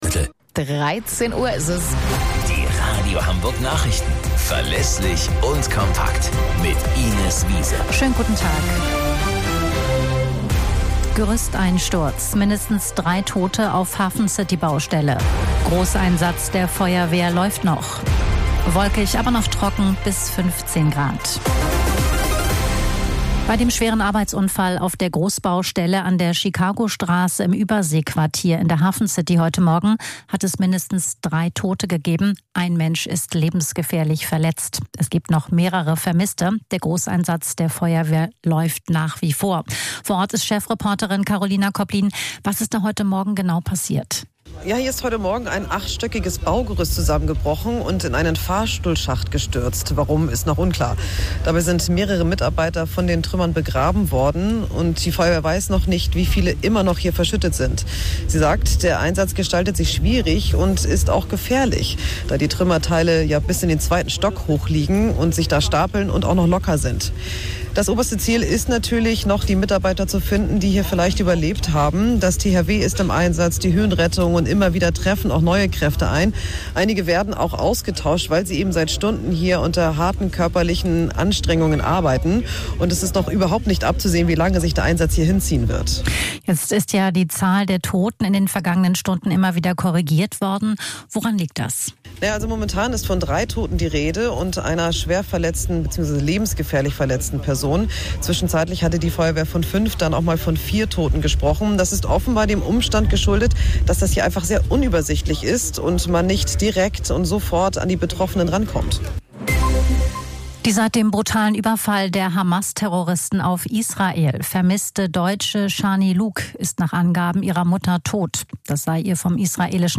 Radio Hamburg Nachrichten vom 28.08.2023 um 04 Uhr - 28.08.2023